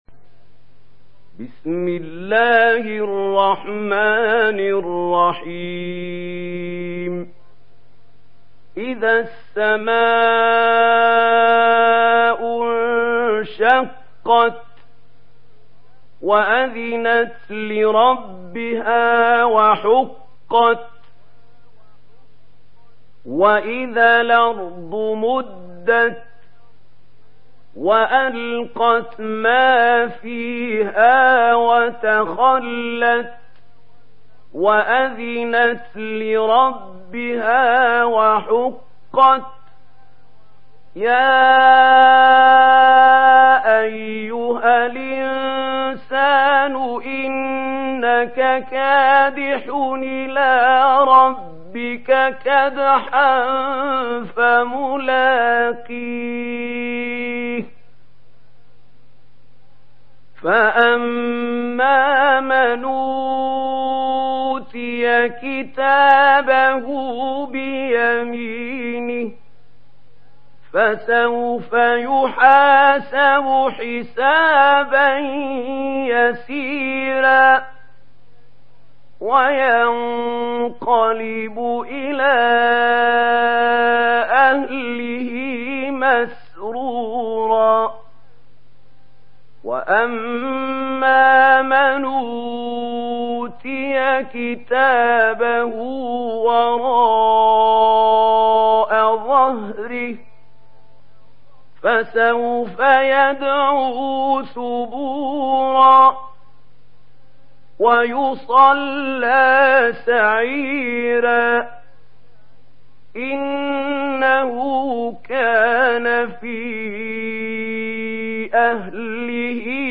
Surah Al-Inshiqaq MP3 in the Voice of Mahmoud Khalil Al-Hussary in Warsh Narration
Surah Al-Inshiqaq MP3 by Mahmoud Khalil Al-Hussary in Warsh An Nafi narration.
Murattal Warsh An Nafi